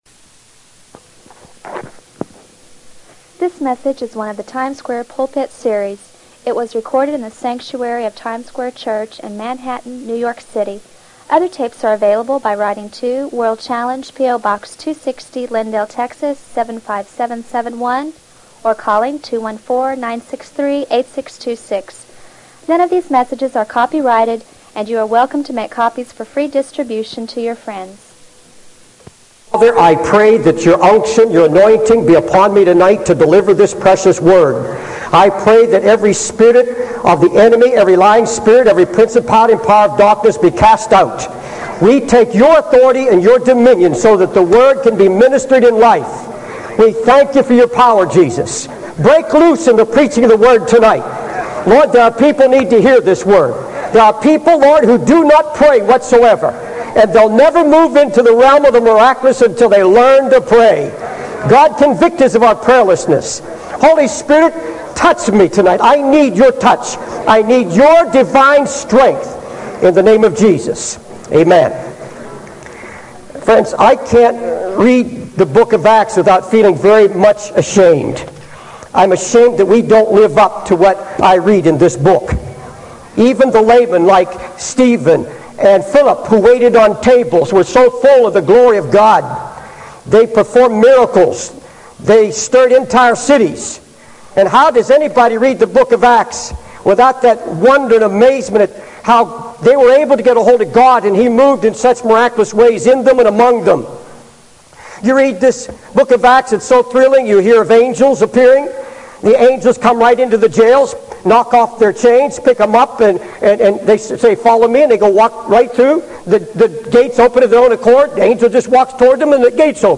In this sermon, the preacher discusses the story of Saul and how he received detailed direction from the Lord.